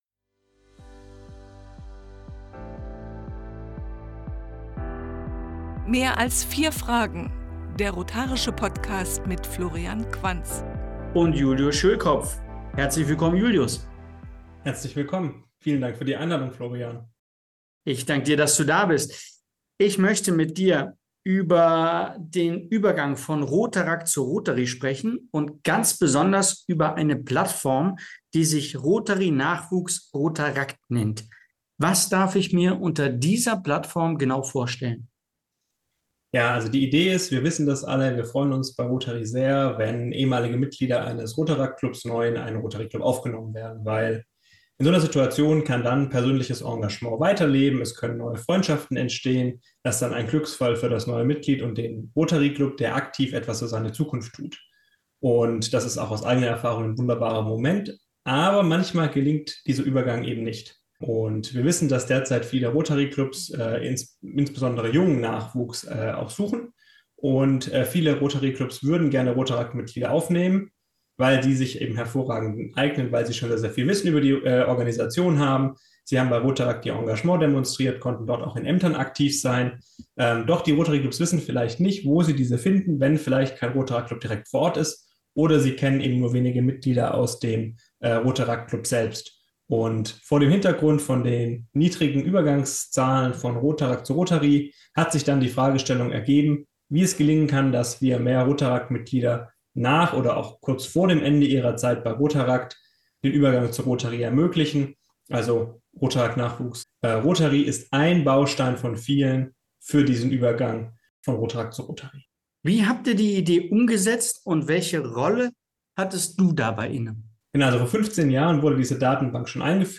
Diesmal im Gespräch: